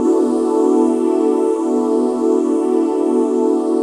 cch_synth_dream_125_F#m.wav